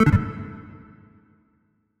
click-close.wav